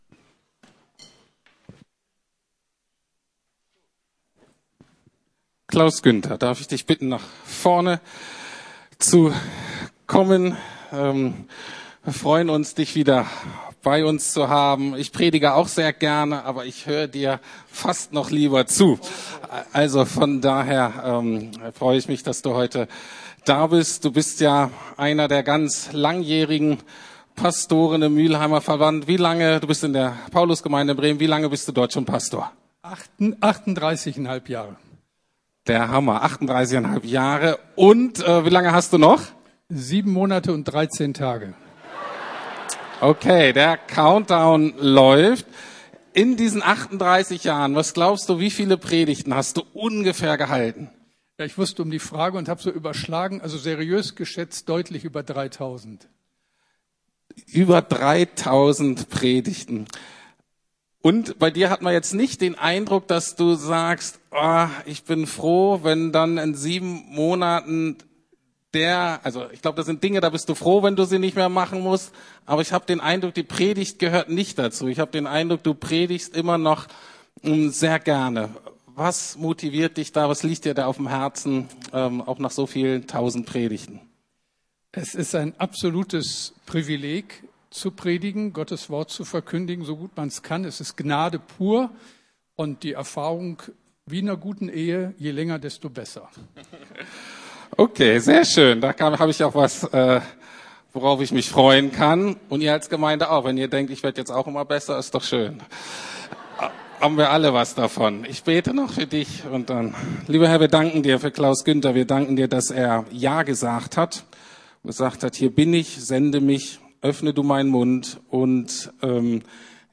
Allein die Gnade - Sola Gratia ~ Predigten der LUKAS GEMEINDE Podcast